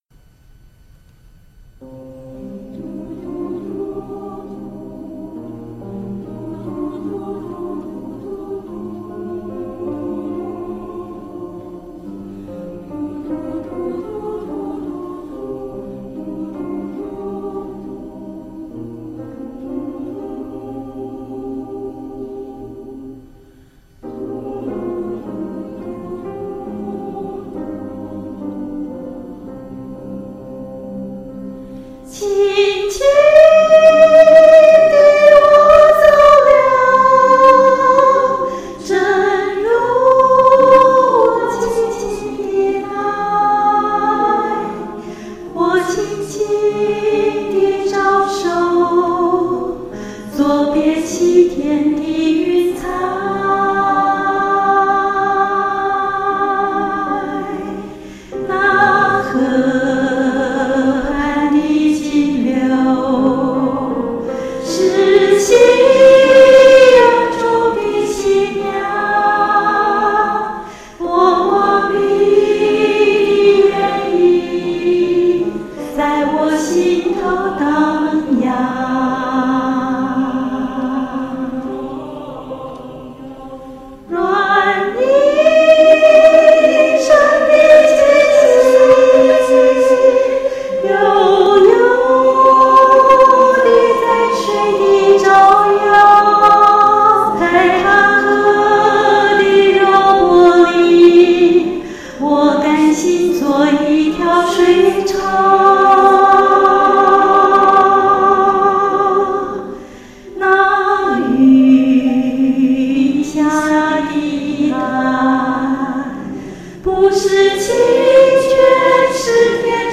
不是姐姐唱得好， 是伴奏的合唱和声太美。